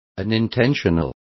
Also find out how involuntario is pronounced correctly.